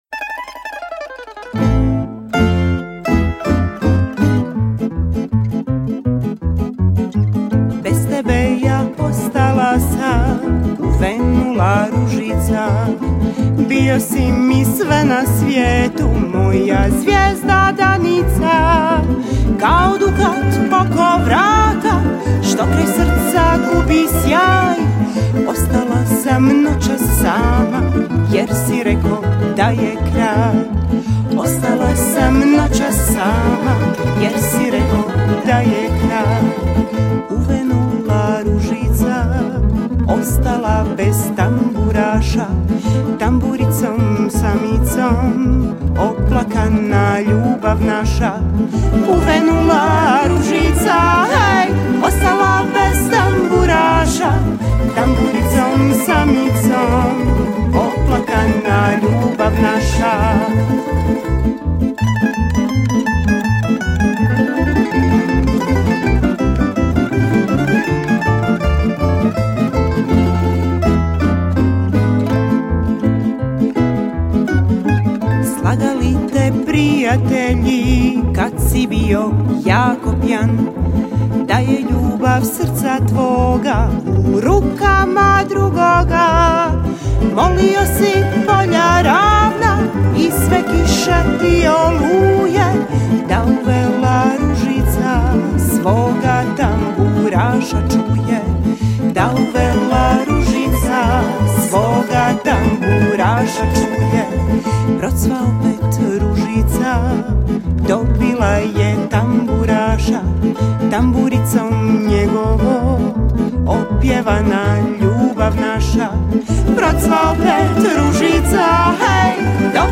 Na festivalu je ove godine nastupilo 19 izvođača s novim autorskim pjesmama, od toga je bilo 8 tamburaških sastava te 11 solista.